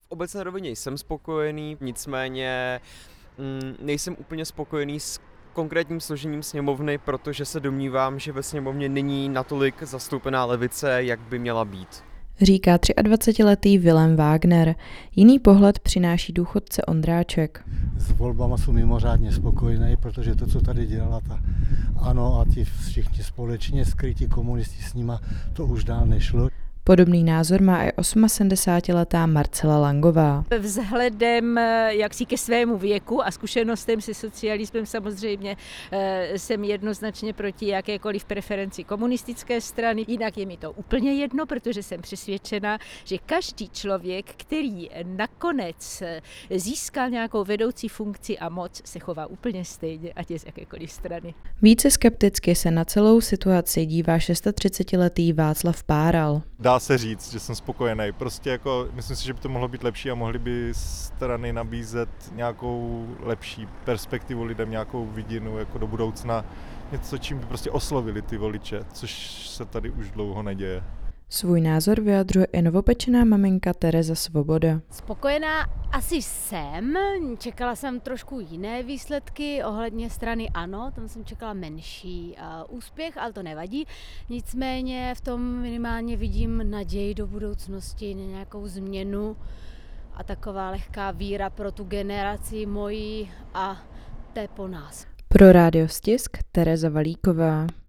Anketa: Pohled Brňanů na letošní parlamentní volby